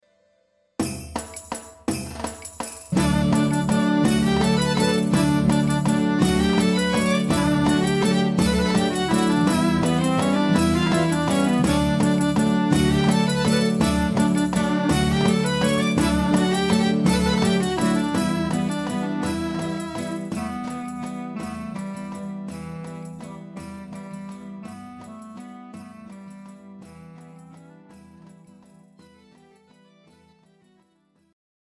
Dimotika